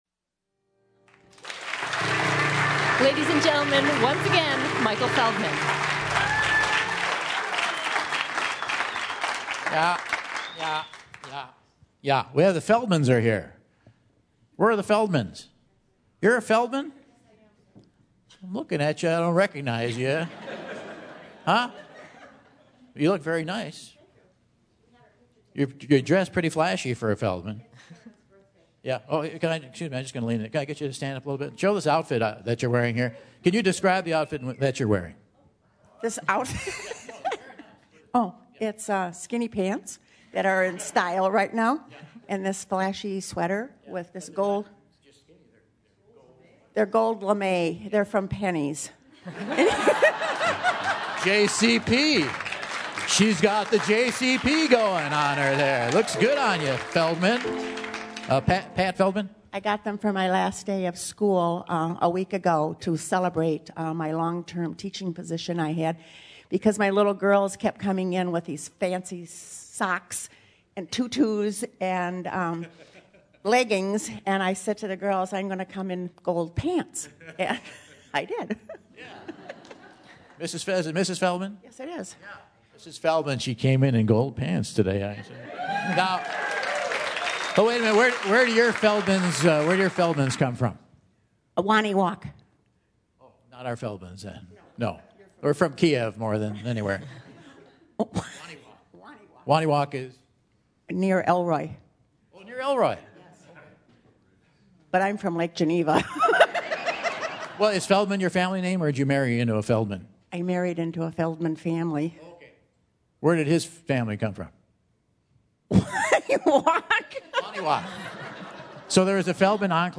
Quiz B - December 8, 2012